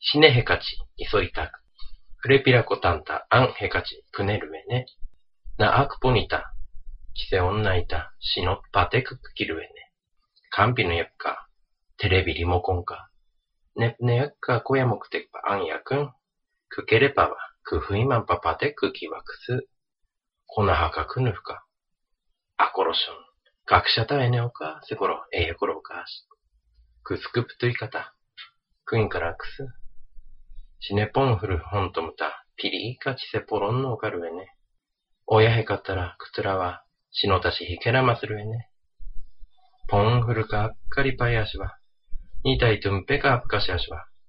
By the way, this was recorded by a non-native speaker of this language.